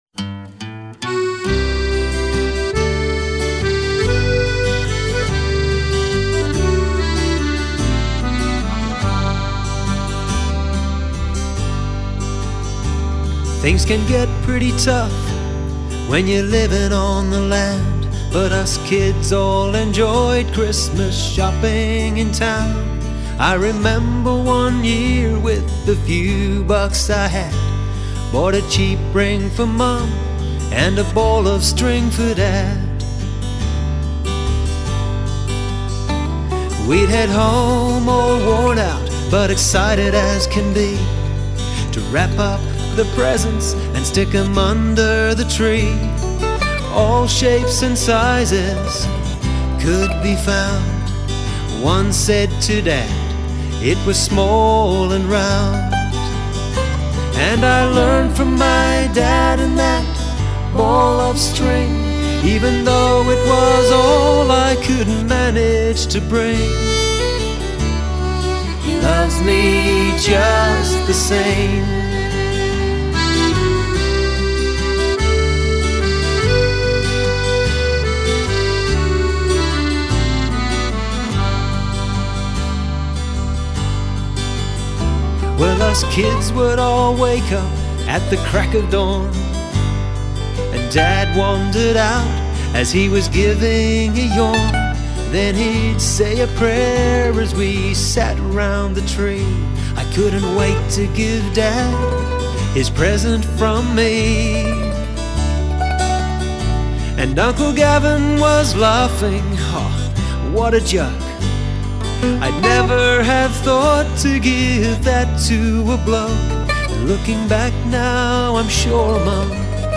Christmas song